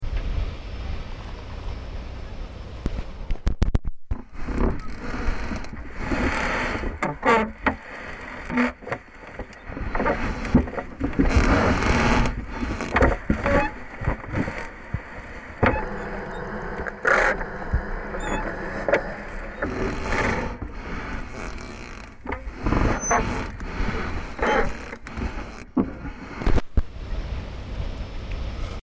As I got closer, I heard the sharp screech of metal joints — the separate segments of the dock moving up and down with the waves. I quickly pulled out my contact microphone and captured it. The result is raw and abrasive — so take care if you’re listening at full volume.